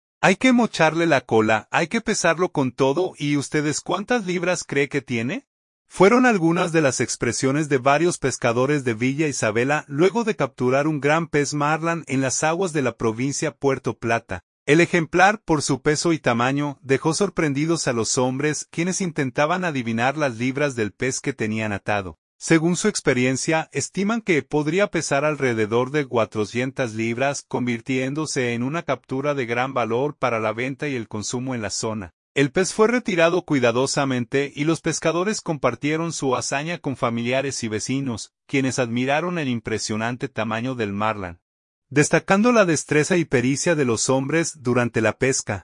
PUERTO PLATA.-Hay que mocharle la cola”, “Hay que pesarlo con todo” y “¿Y ustedes cuántas libras cree que tiene?”, fueron algunas de las expresiones de varios pescadores de Villa Isabela luego de capturar un gran pez marlin en las aguas de la provincia Puerto Plata.